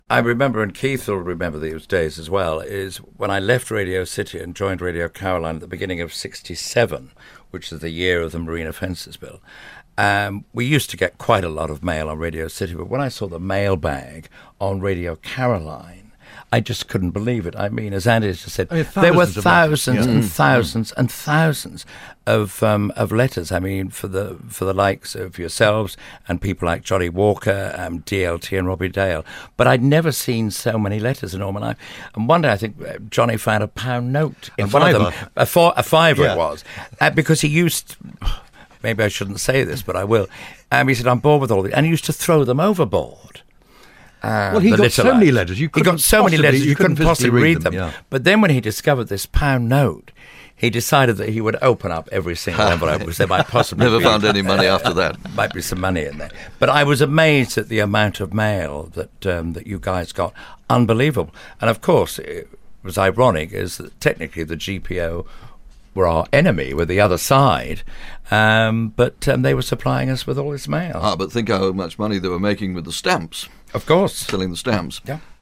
BBC Radio Norfolk, 8th April 2014.